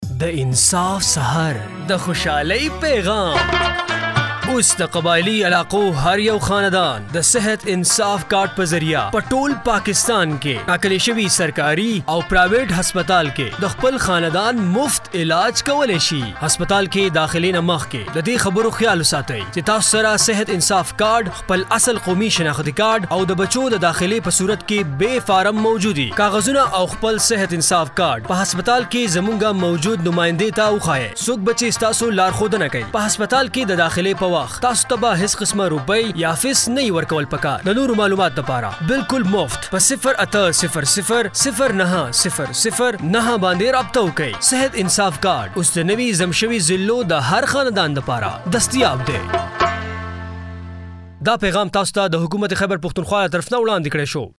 Sehat Insaf Card – Radio Spots